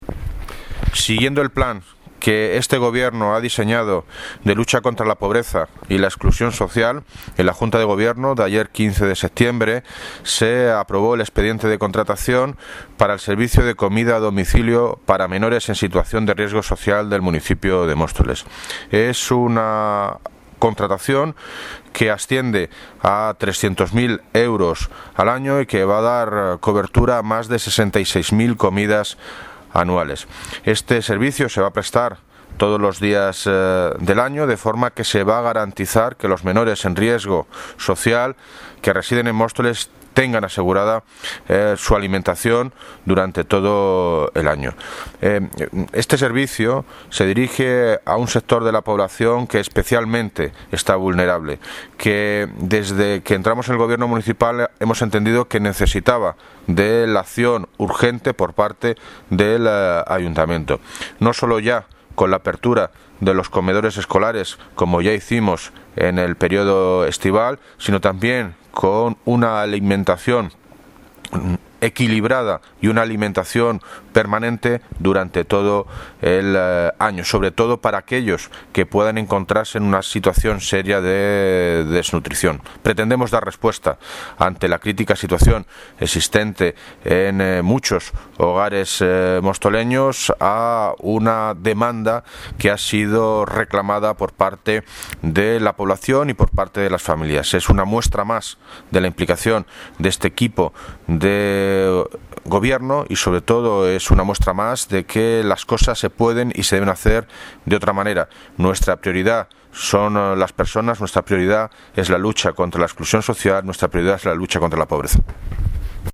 Audio - David Lucas (Alcalde de Móstoles ) Sobre Garantias de servicio de comidas a domicilio